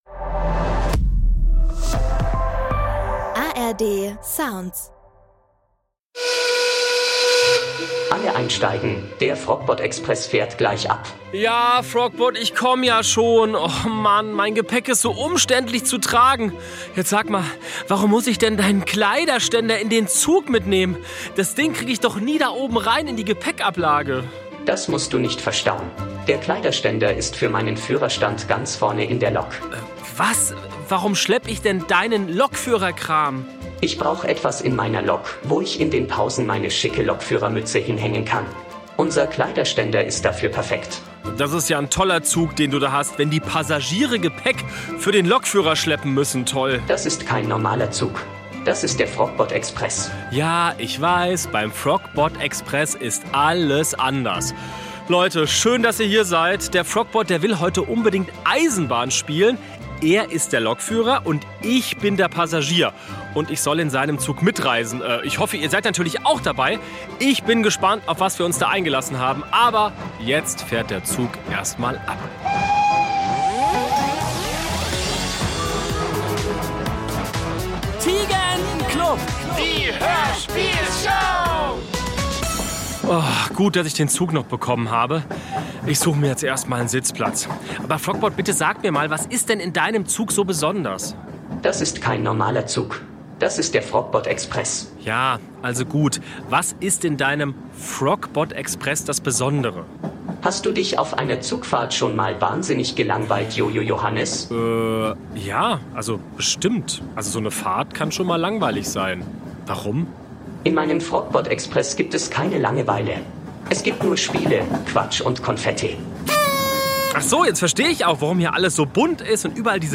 lustige Geräusche- und Lach-Spiele